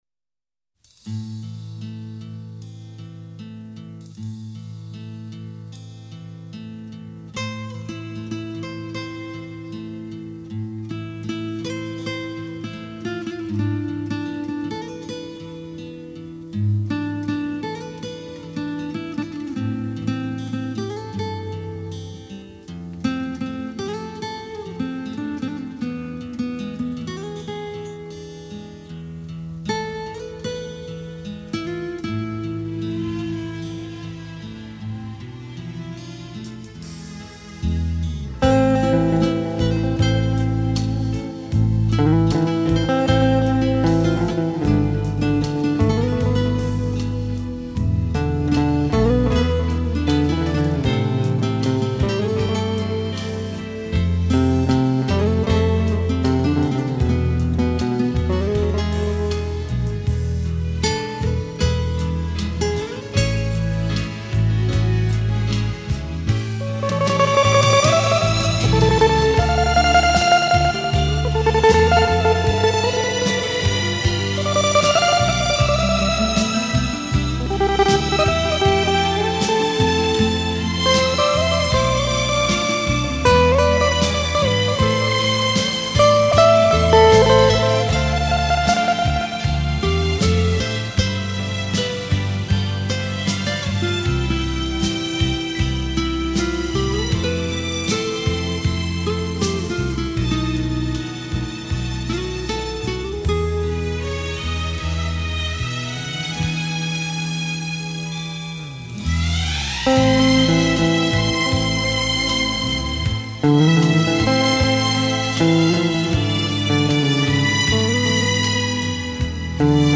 * Thể loại: Việt Nam